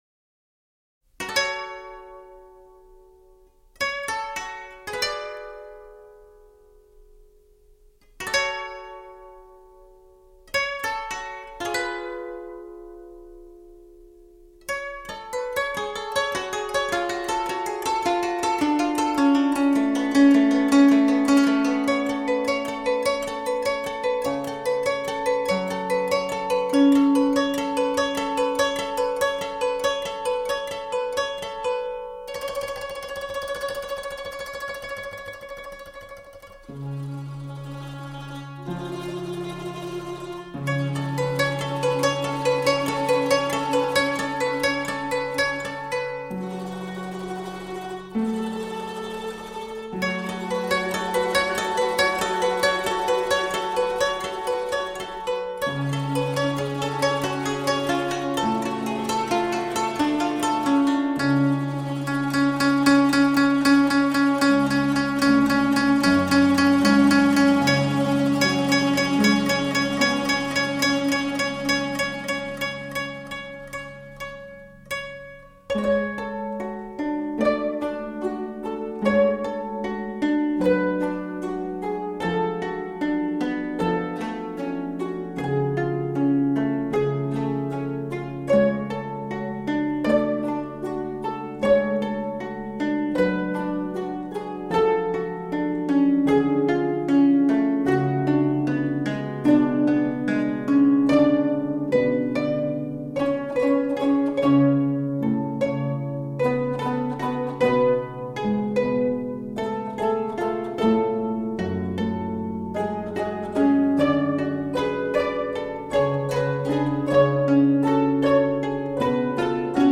協奏曲—
十八絃ソロ
(箏群)
十五絃
十七絃
絃ソロが美しく深みを伴って奏でられるこ
とと、十 八絃が箏群の音響と重なりあって